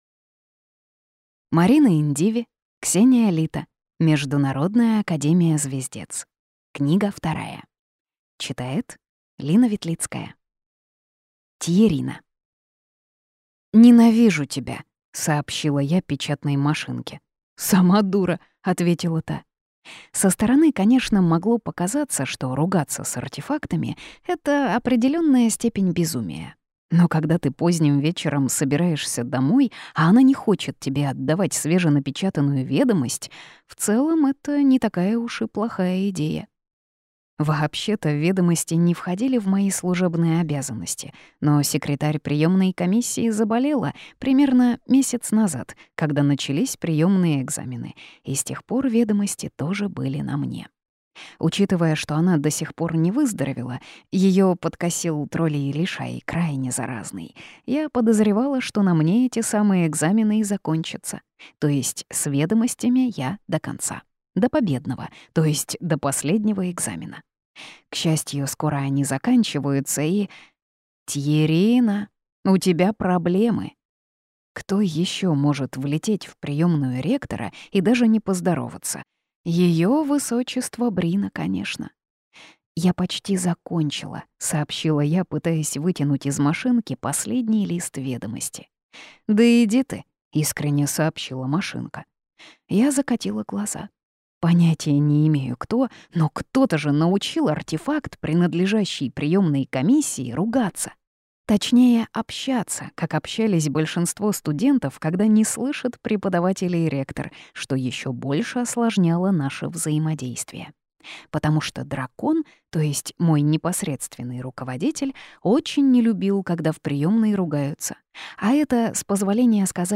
Слушать аудиокнигу Невеста проклятого полностью